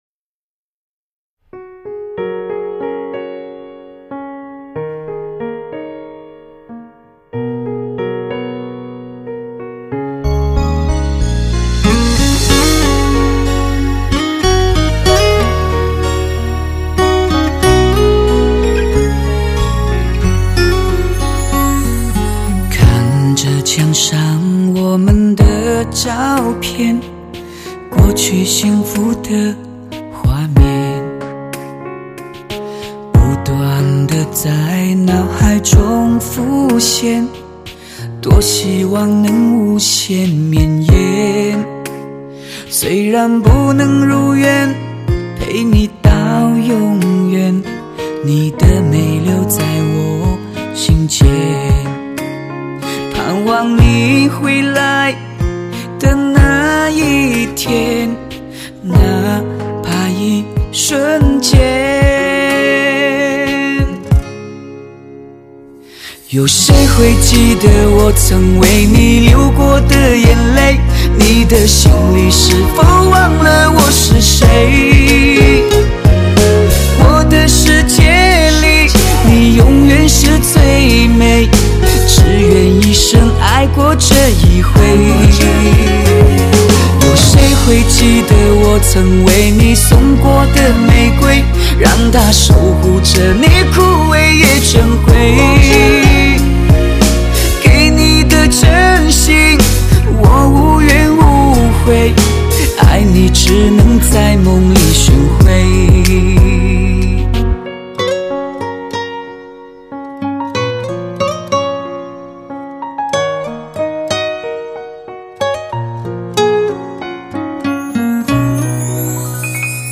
流行情歌
倍受市场推崇的汽车音响专用CD，高解像度及透明度！
发烧的人声试音碟最精华的流行音乐，录音清丽脱俗，一尘不染。